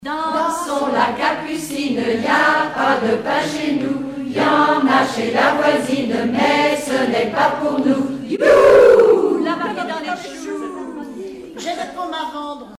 ronde à s'accroupir
Regroupement de chanteurs du canton
Pièce musicale inédite